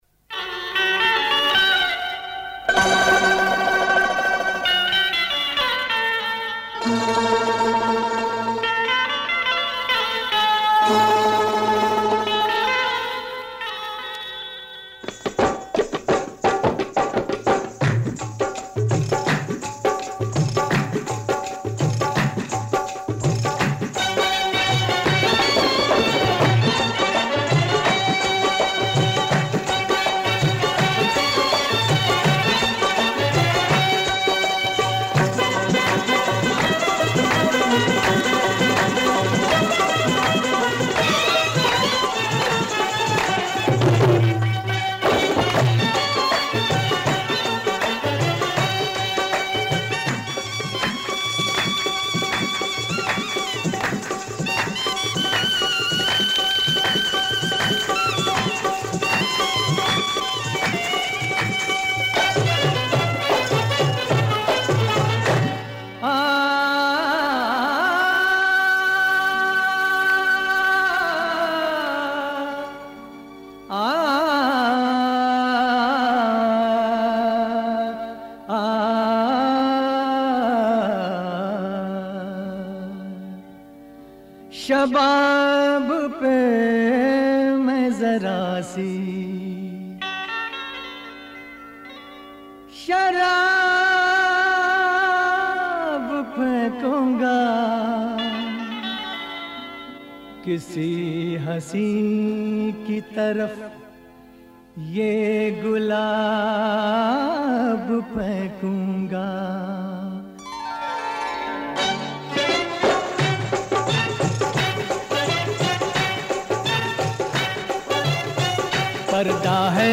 Qawwalis From Old Films